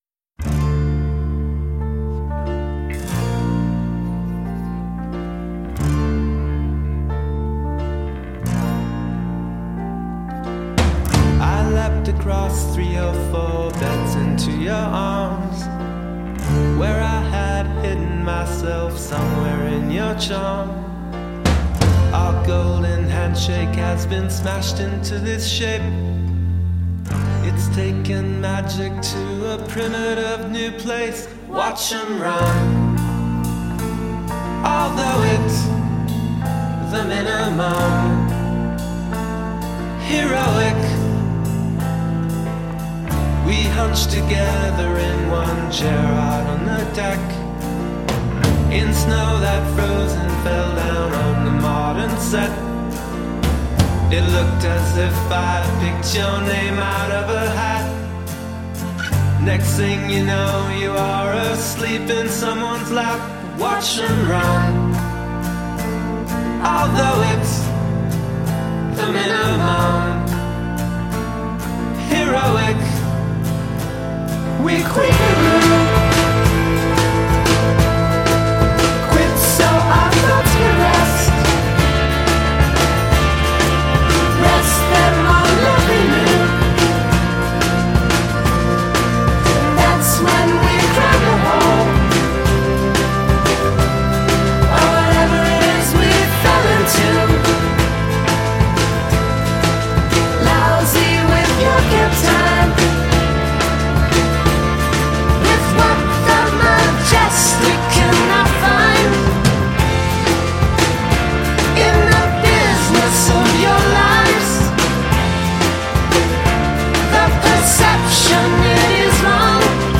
but her sledgehammer voice just crushes everyone else.